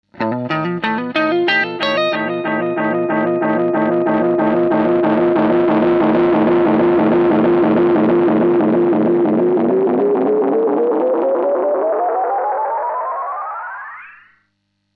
Аналоговый дилэй Electro-Harmonix Deluxe Memory Man
Massive Overload (175 кБ)
massive_overload.mp3